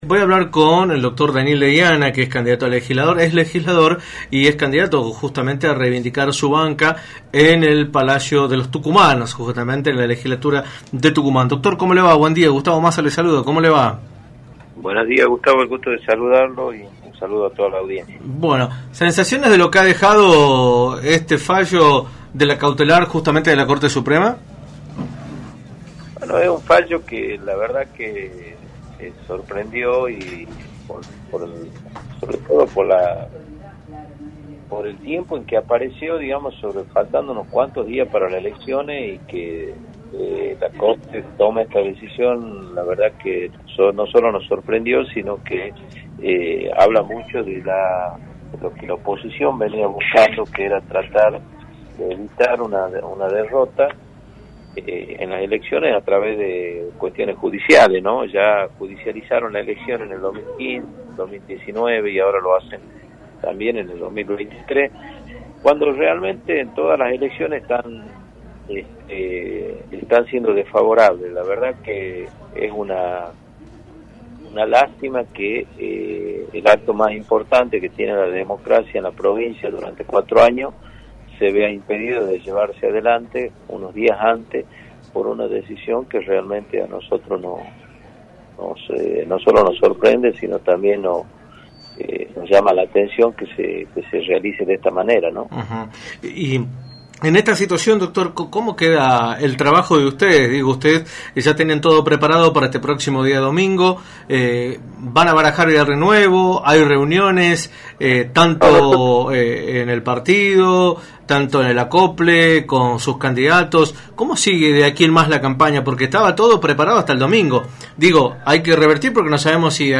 Daniel Deiana, Legislador y candidato a renovar su banca en la Legislatura, analizó en «La Mañana del Plata» por la 93.9, las consecuencias que trae aparejado el fallo de la Corte Suprema de Justicia de la Nación sobre suspender las elecciones provinciales que estaban previstas para el 14 de mayo.